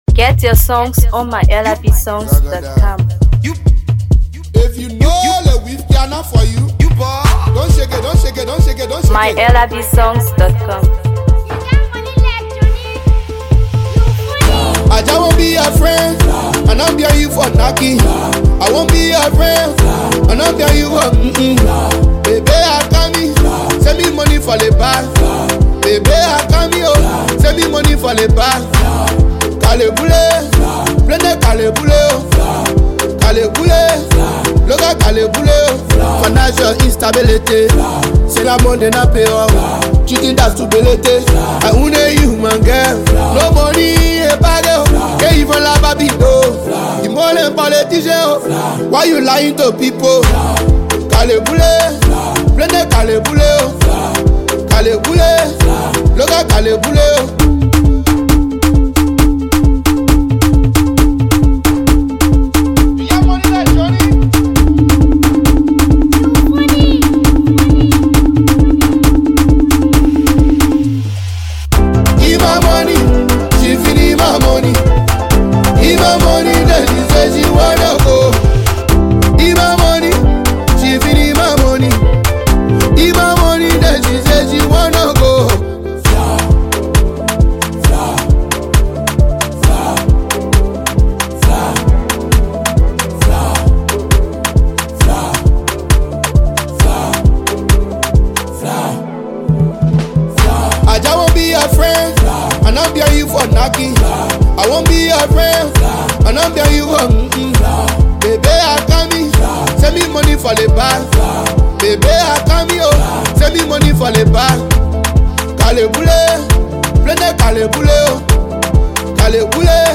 blending catchy melodies with powerful lyrics.